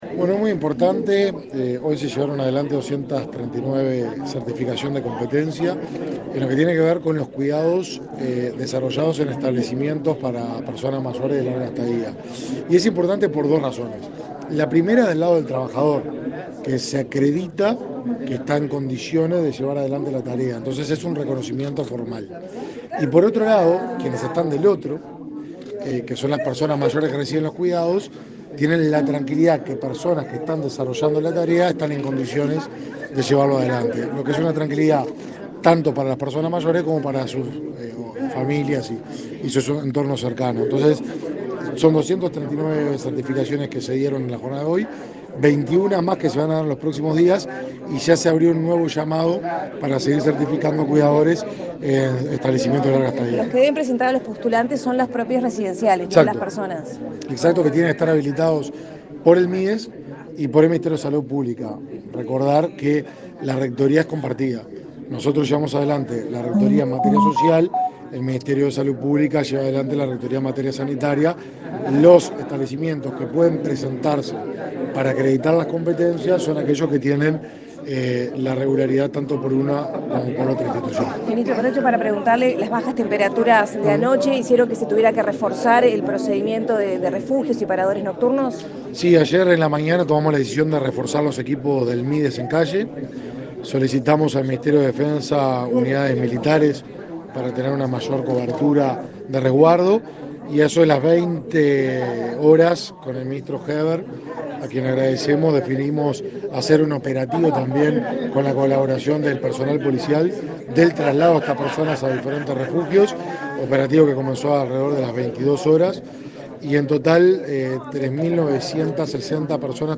Declaraciones del ministro de Desarrollo Social, Martín Lema
Tras participar en el acto por entrega de certificación de competencias en cuidados y el lanzamiento de los centros de larga estadía, organizada por el Instituto Nacional de Empleo y Formación Profesional y la Dirección de Cuidados del Ministerio de Desarrollo Social, este 19 de agosto, el ministro Lema efectuó declaraciones a la prensa.